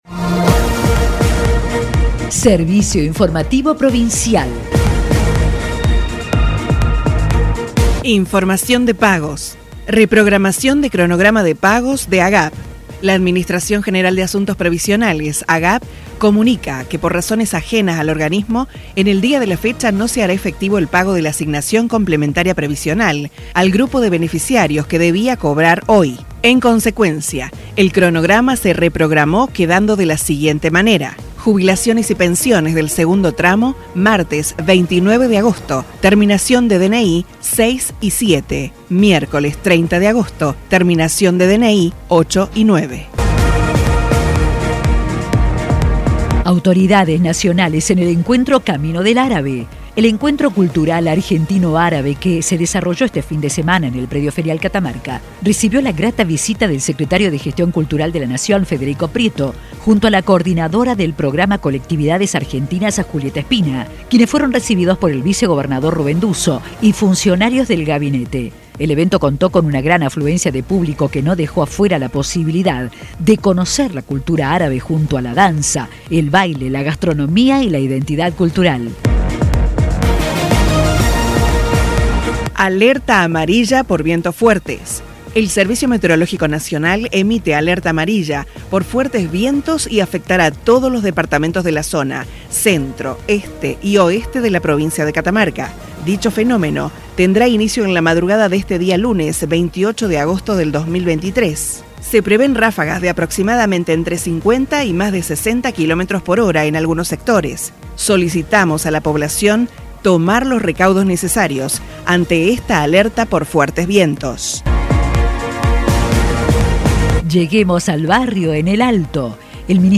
Resumen Informativo 28-08